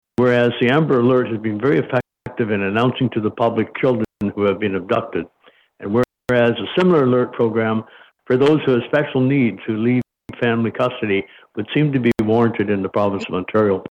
At a meeting of Hastings County Council on Thursday, Centre Hastings Mayor Tom Deline brought the issue to council’s attention.